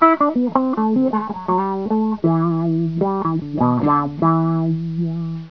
WHA-EFFECTS